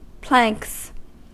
Ääntäminen
Ääntäminen US Haettu sana löytyi näillä lähdekielillä: englanti Planks on sanan plank monikko.